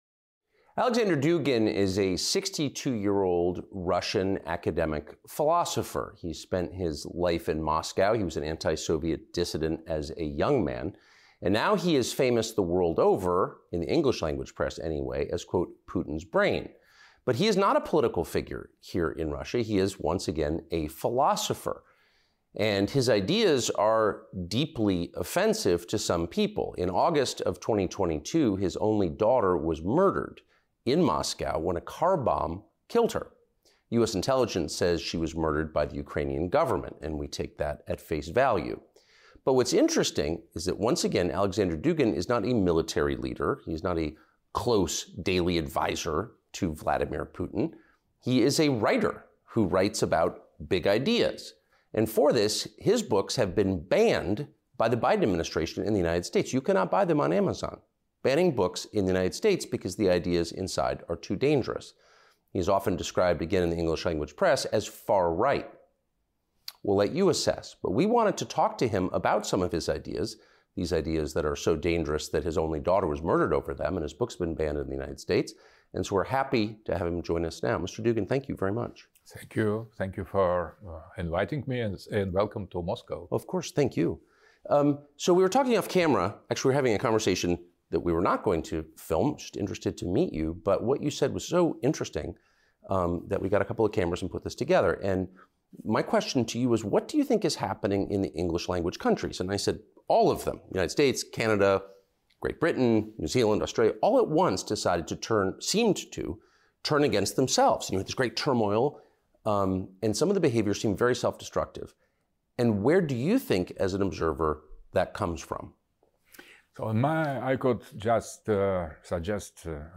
Exclusive interview with "Putin's brain" Dugin: No one wins in the Russia-Ukraine War